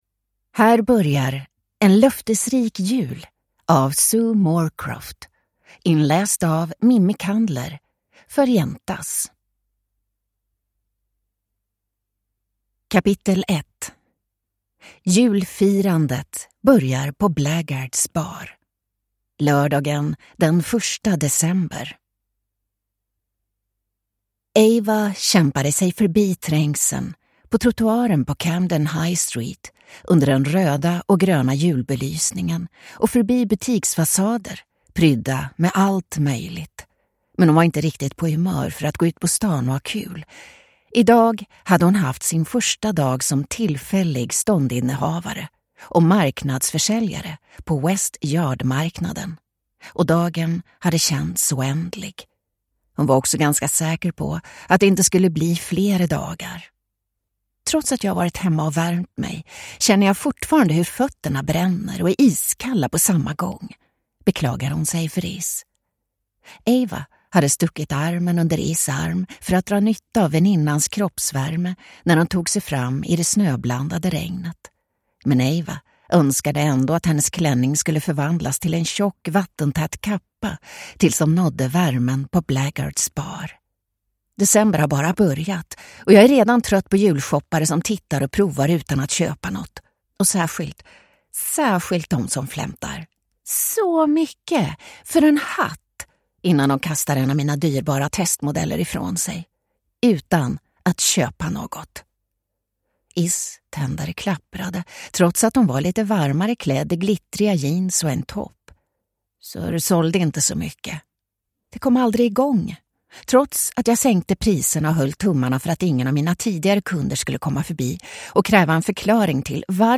En löftesrik jul – Ljudbok